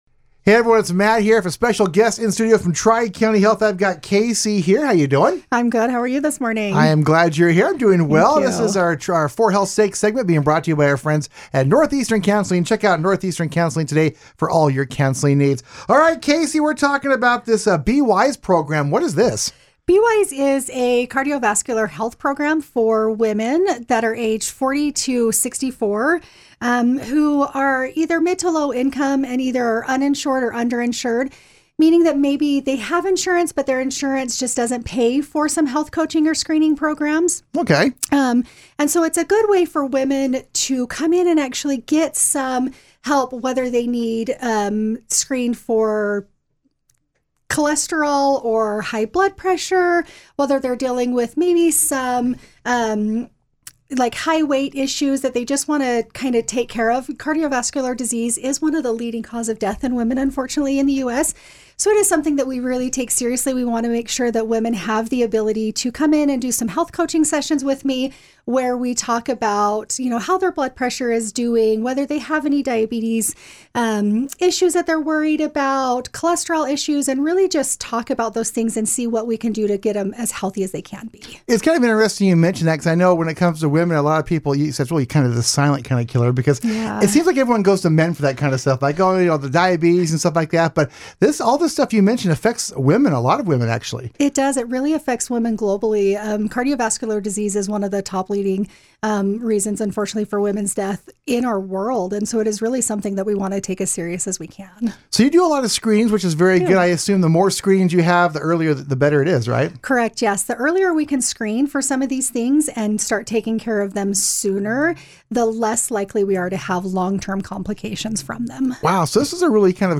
Weekly Radio Spots